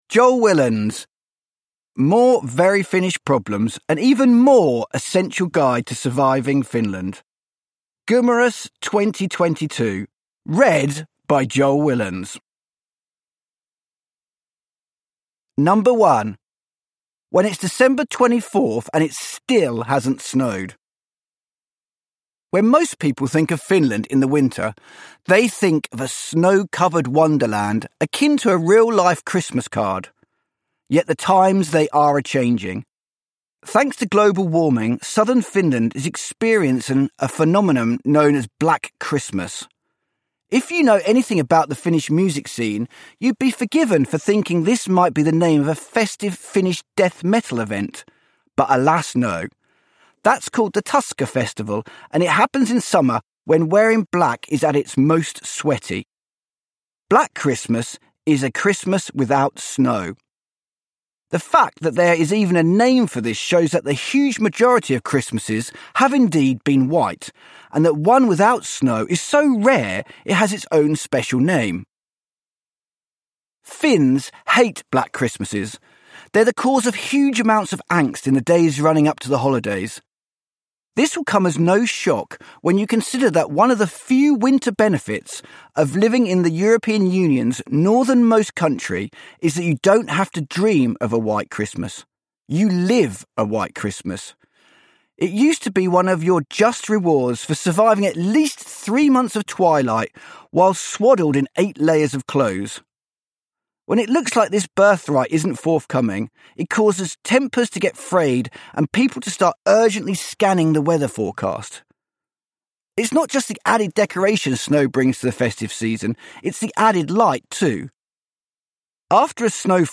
More Very Finnish Problems – Ljudbok – Laddas ner